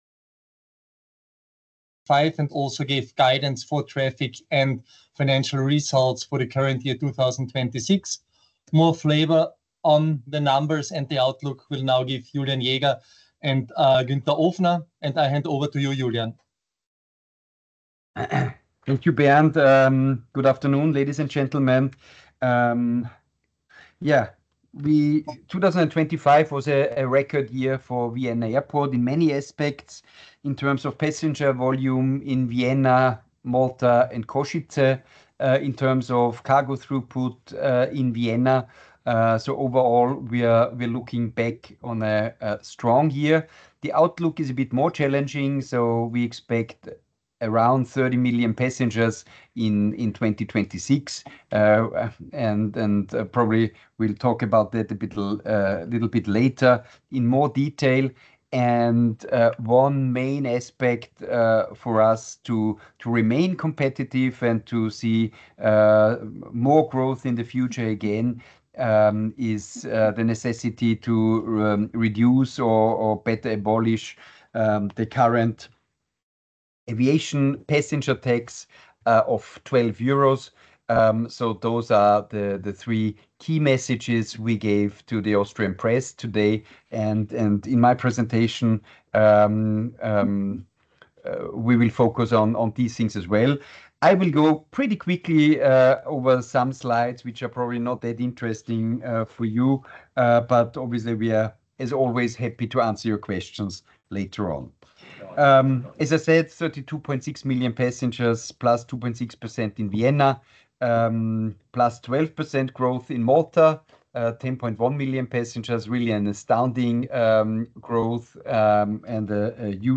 Replay of the Conference Call Presentation of the Traffic Results 2025 & Outlook for 2026